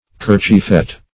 Search Result for " kerchiefed" : The Collaborative International Dictionary of English v.0.48: Kerchiefed \Ker"chiefed\, Kerchieft \Ker"chieft\, a. Dressed; hooded; covered; wearing a kerchief.
kerchiefed.mp3